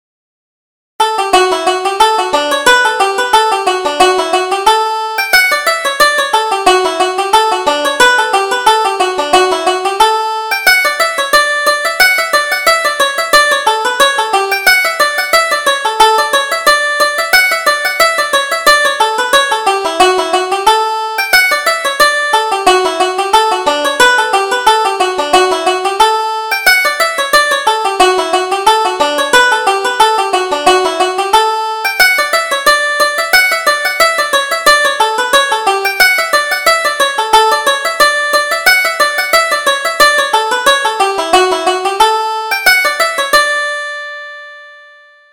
Reel: Welcome Home